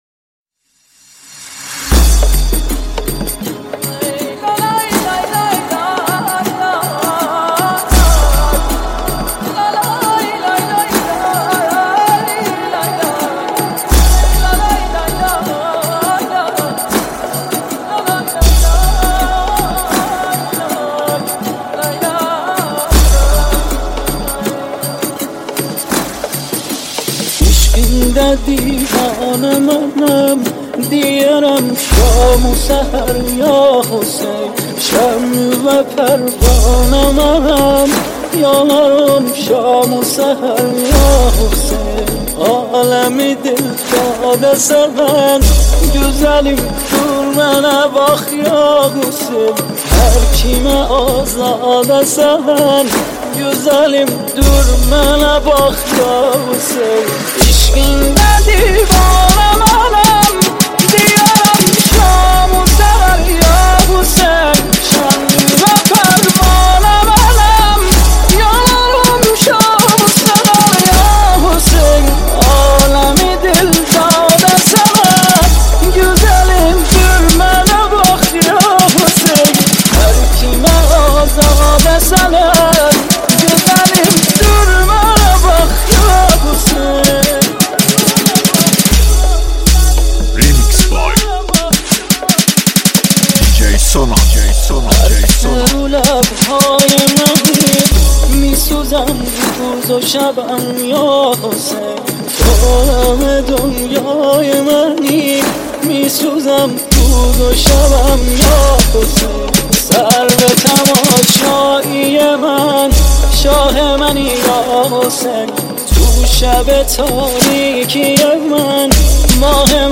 madahi2.mp3